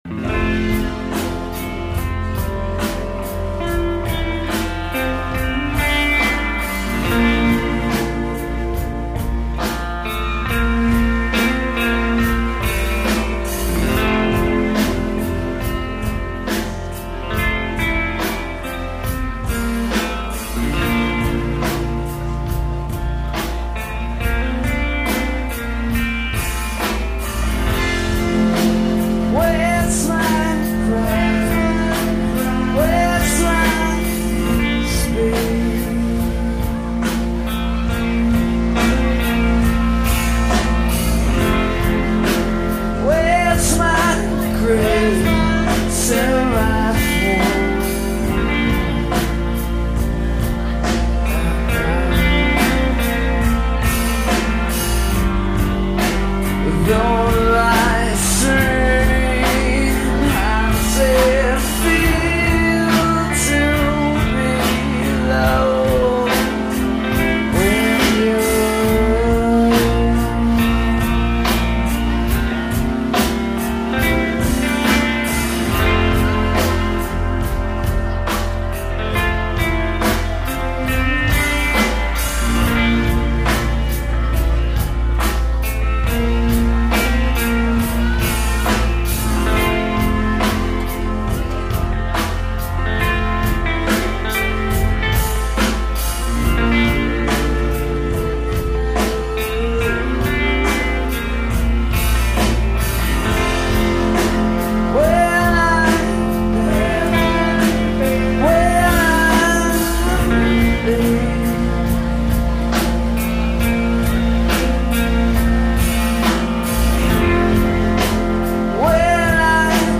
schuba's chicago september 2000